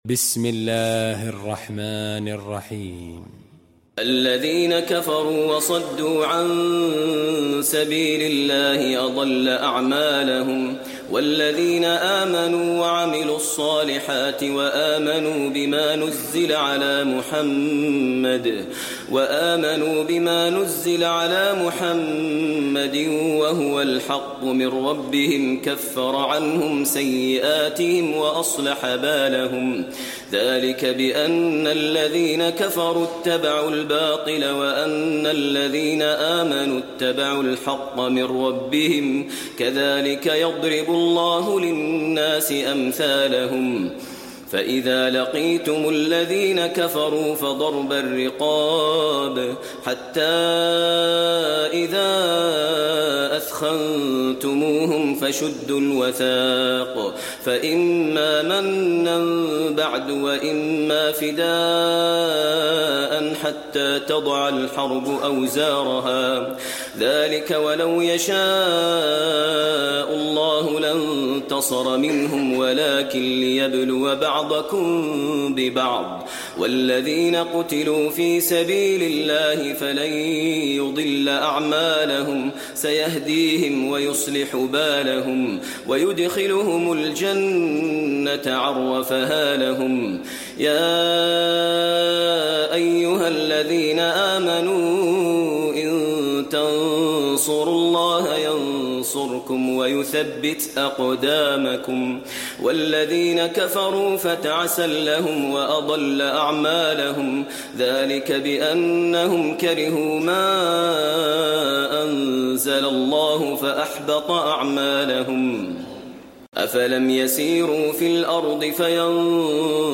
المكان: المسجد النبوي محمد